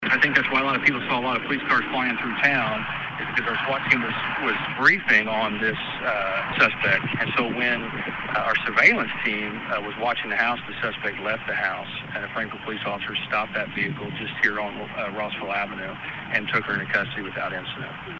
A murder suspect was taken into custody late this afternoon in Frankfort. Rossville Avenue was shut down after police had it blocked off while assisting Lafayette Police Department with apprehending the suspect. Frankfort Police Chief Scott Shoemaker says it certainly alarmed the public when police cars took off Tuesday afternoon.